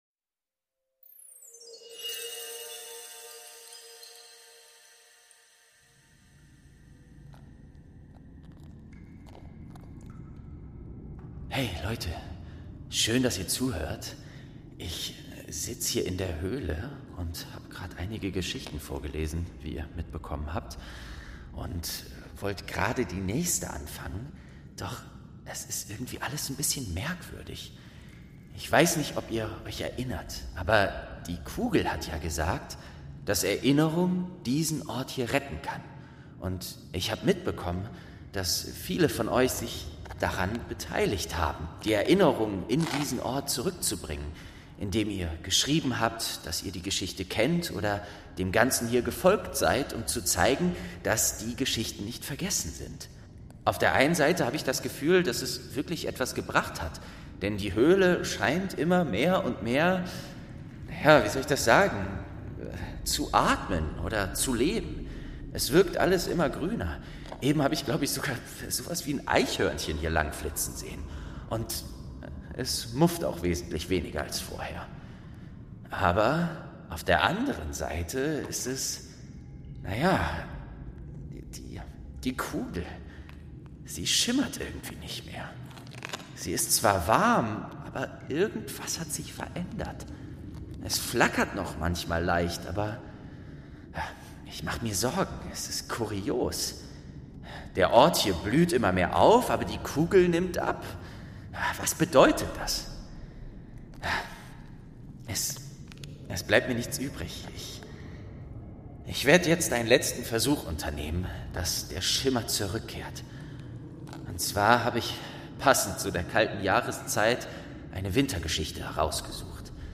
12. Die Schneekönigin | Staffel 1 ~ Märchen aus der verschollenen Bibliothek - Ein Hörspiel Podcast